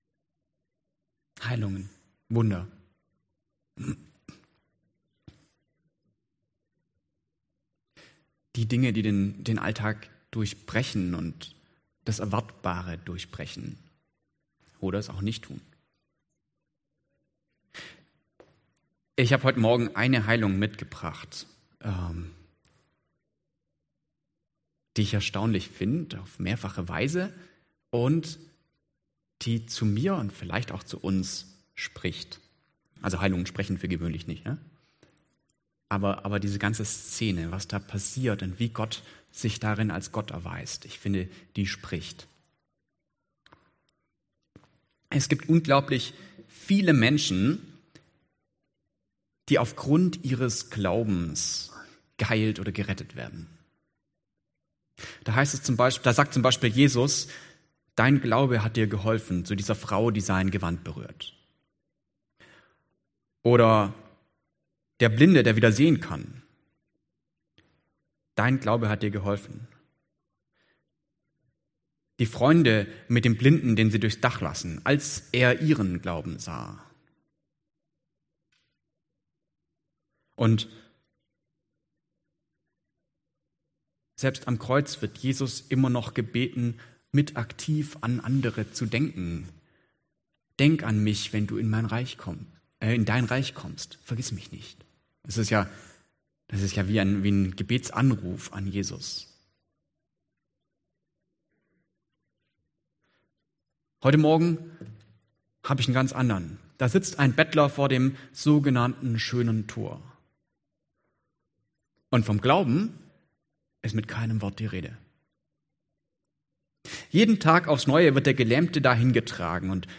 Passage: Apg 3, 1-10 Dienstart: Gottesdienst Themen